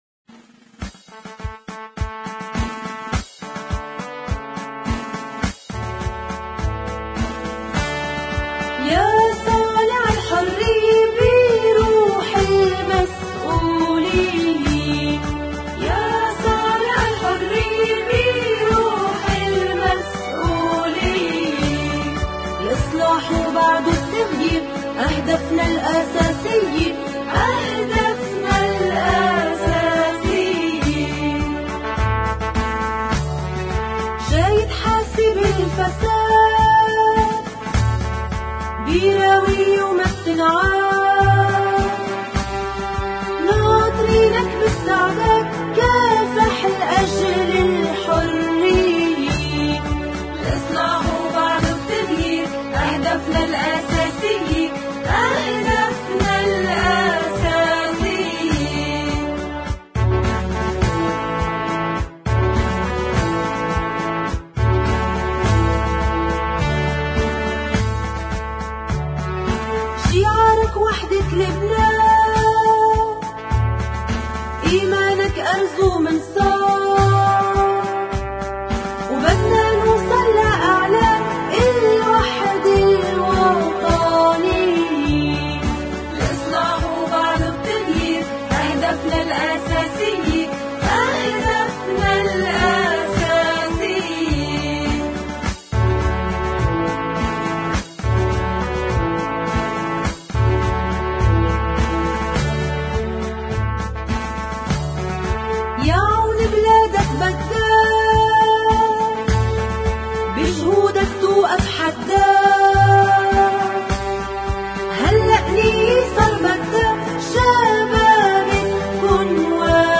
اغنية